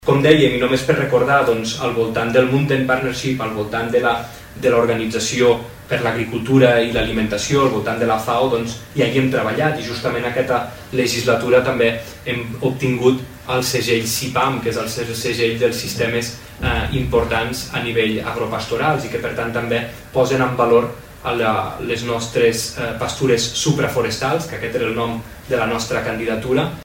Precisament en aquest sentit, el ministre de Medi Ambient, Agricultura i Ramaderia, Guillem Casal, ha recordat iniciatives de Govern relacionades amb la sostenibilitat.